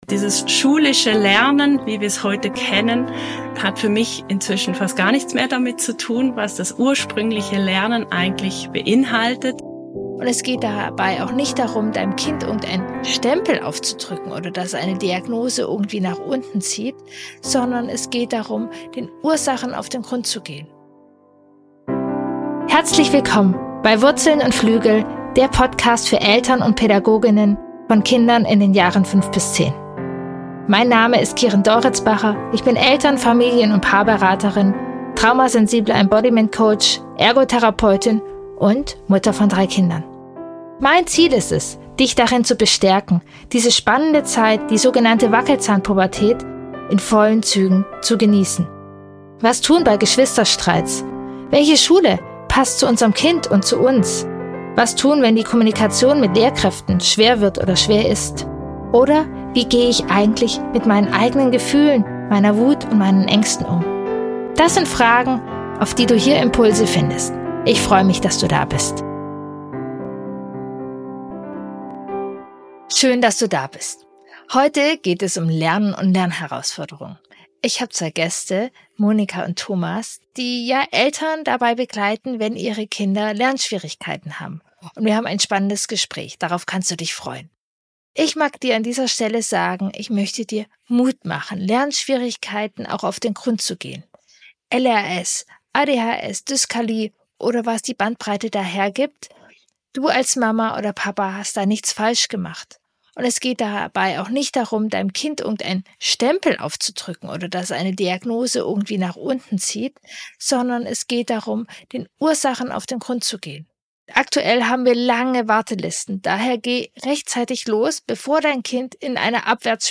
#115 Lernschwierigkeiten bedürfnisorientiert begegnen – Gespräch